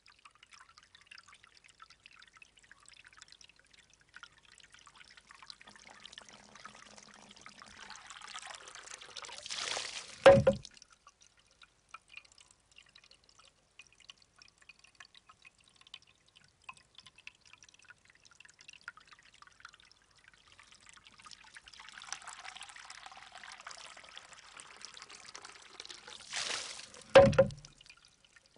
Shishi_odoshi.R.wav